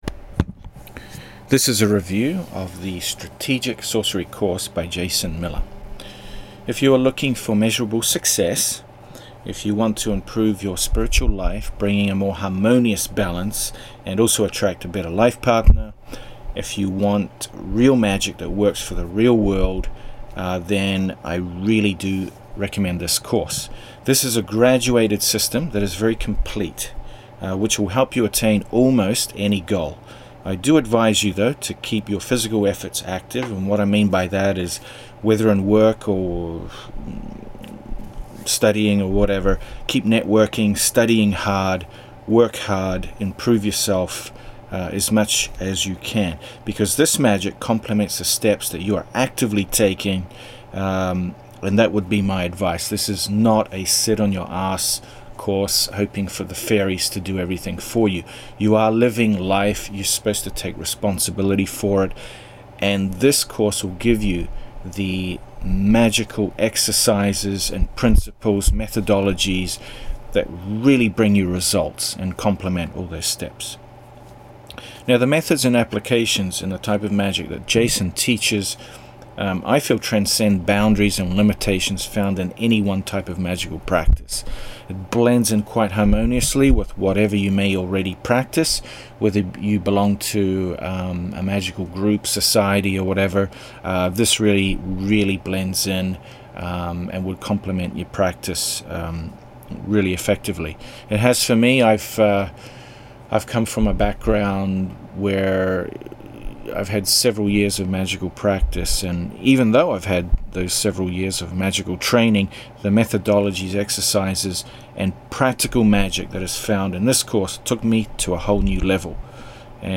AUDIO REVIEW OF STRATEGIC SORCERY